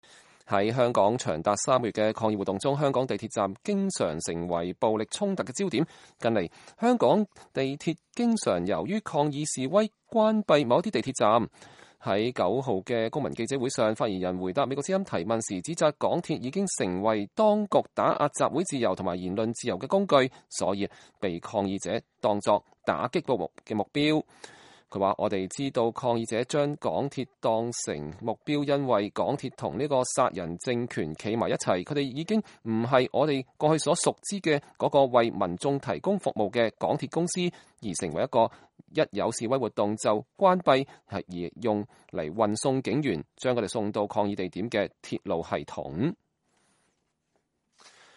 香港抗議者9日記者會（美國之音圖片）